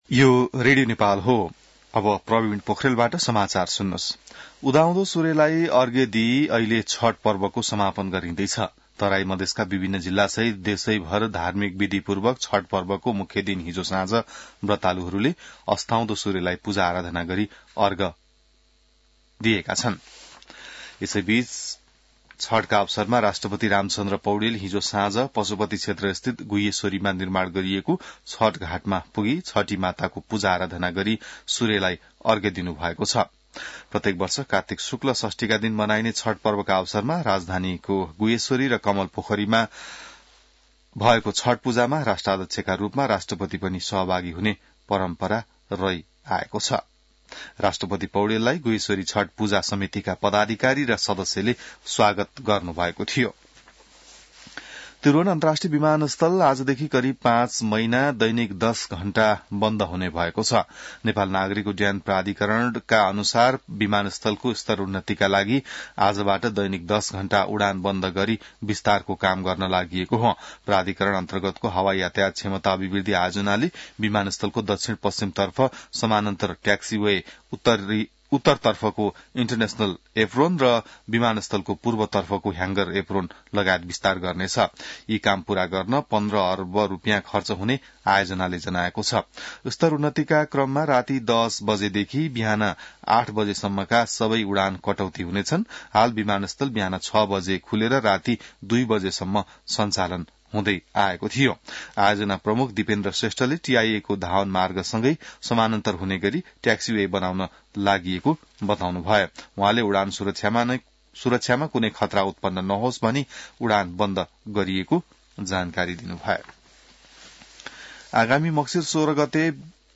An online outlet of Nepal's national radio broadcaster
बिहान ६ बजेको नेपाली समाचार : २४ कार्तिक , २०८१